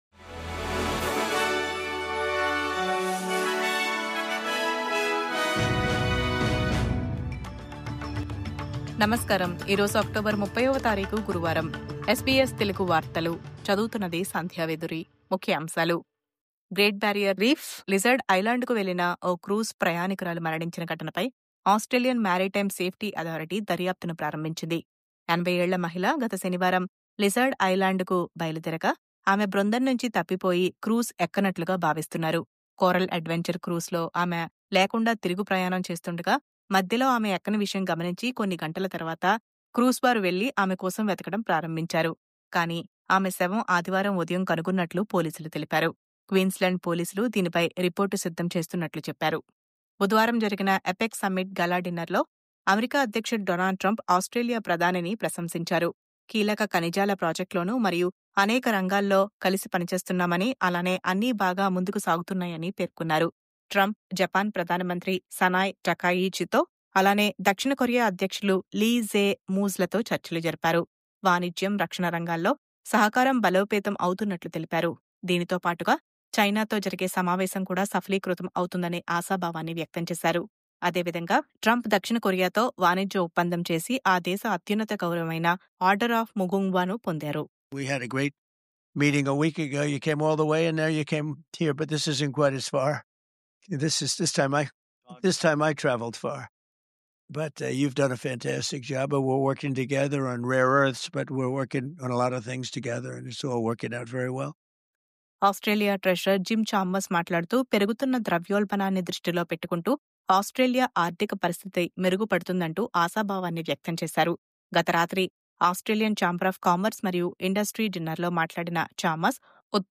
News update: గ్రేట్ బారియర్ రీఫ్‌లో విషాదం...లిజర్డ్ ఐలాండ్‌కు క్రూయిజ్‌లో వెళ్లిన మహిళ మృతి..